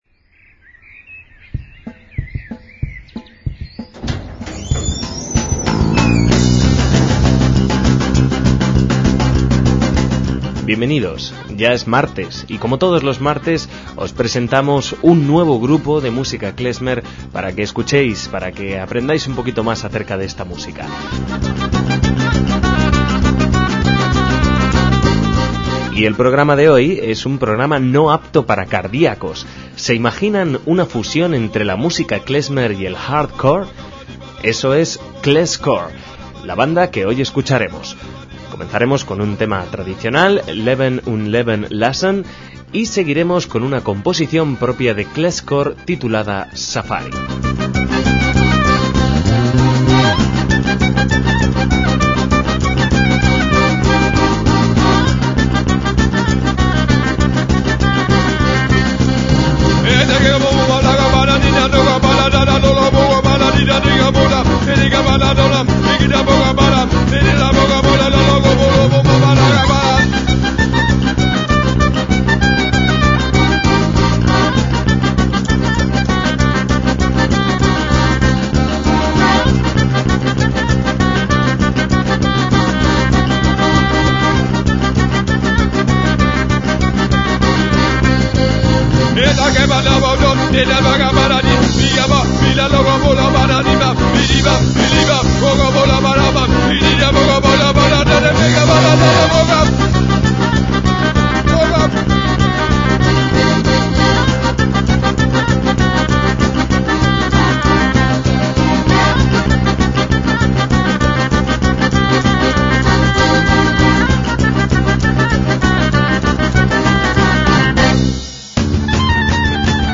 MÚSICA KLEZMER - Que el klezmer da para muchas interpretaciones, lo sabíamos, pero ¿para música hardcore?, ¿con oboe y acordeón?